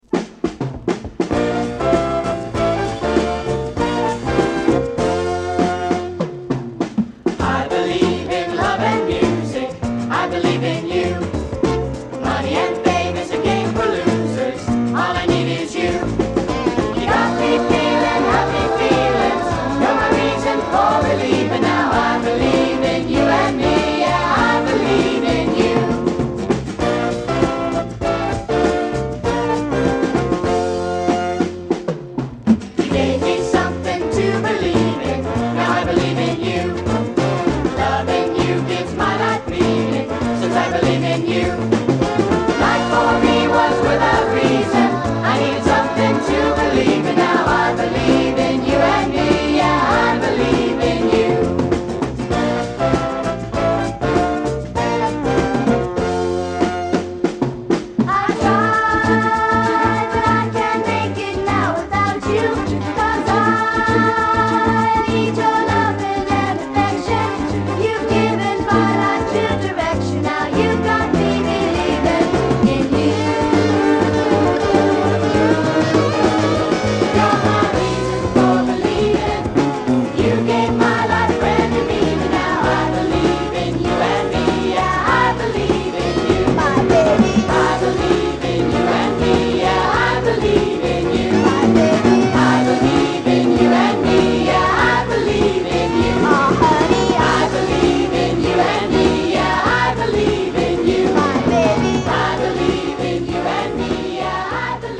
KIDS SOFT ROCKコーラスの大傑作！大人気キッズソウル